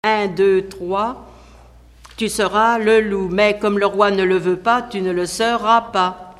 Mémoires et Patrimoines vivants - RaddO est une base de données d'archives iconographiques et sonores.
Enfantines - rondes et jeux
Pièce musicale inédite